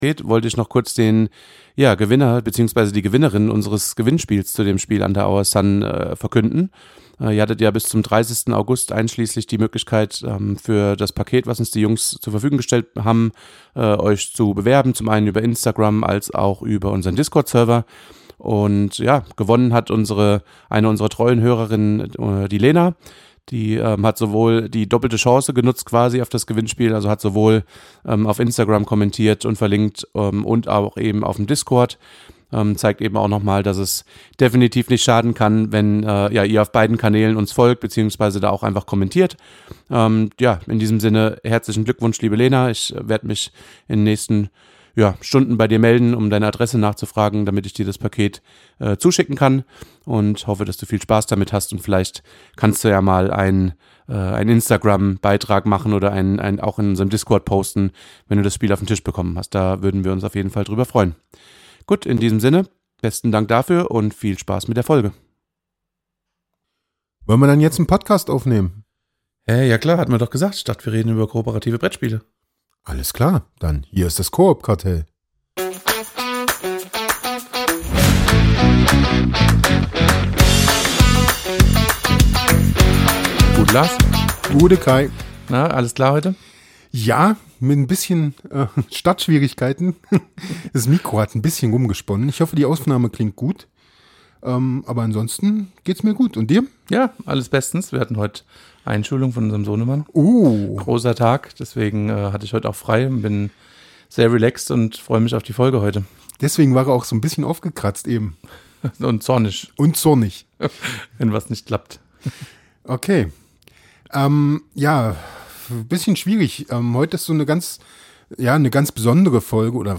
In unserer neuen Folge haben wir die Macher von Under Our Sun zu Gast und tauchen mit ihnen gemeinsam tief in die verwüstete Endzeitwelt ihres einzigartigen Brettspiels ein. Wir sprechen über die Entstehungsgeschichte, spannende Design-Entscheidungen und die Herausforderungen, ein so atmosphärisches Projekt zum Leben zu erwecken.